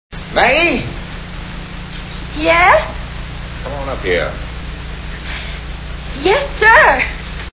Cat on a Hot Tin Roof Movie Sound Bites